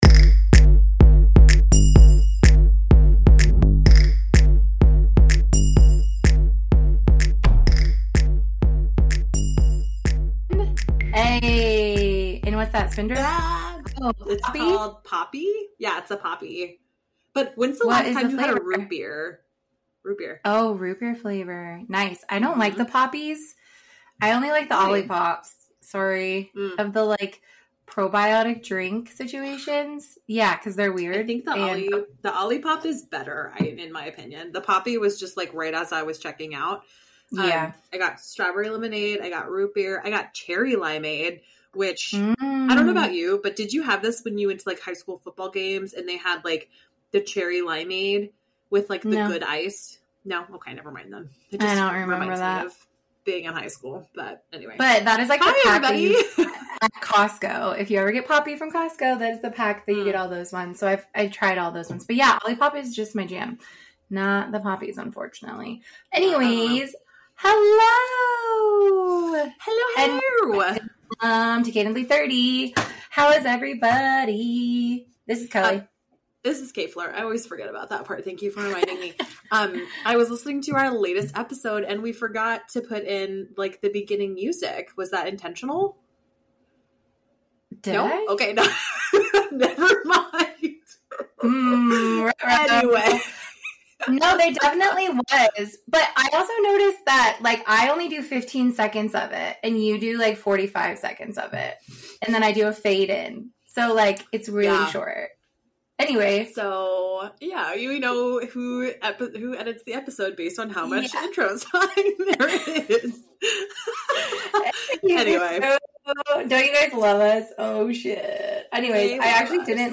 The ladies catch up and give a few favs. Lots of ranting for those of you who missed it :P